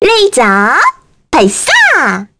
Annette-Vox_Skill1_kr.wav